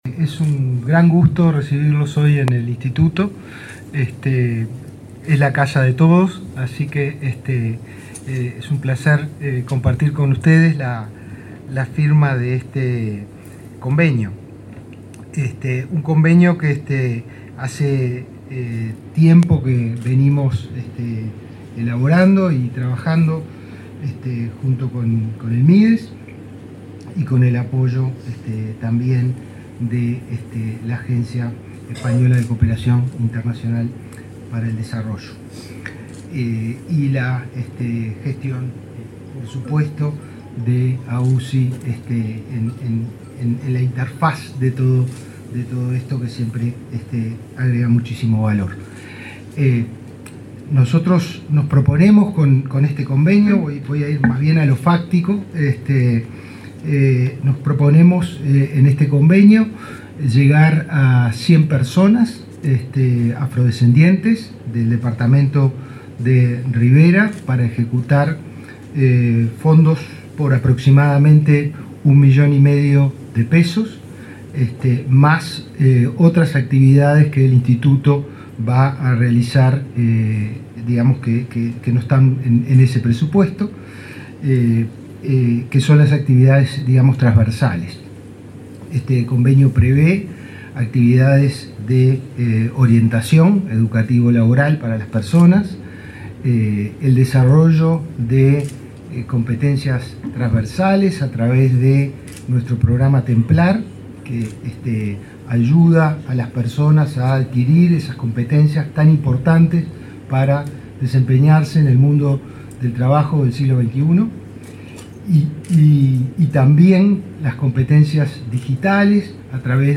Palabras de autoridades en convenio en Inefop
Palabras de autoridades en convenio en Inefop 26/10/2022 Compartir Facebook X Copiar enlace WhatsApp LinkedIn El director del Instituto Nacional de Empleo y Formación Profesional (Inefop), Pablo Darscht; la directora ejecutiva de la Agencia Uruguaya de Cooperación Internacional (AUCI), Claudia Romano, y el ministro de Desarrollo Social, Martín Lema, participaron en la firma de un convenio en apoyo a la implementación del Plan Nacional de Equidad Racial y Afrodescendencia.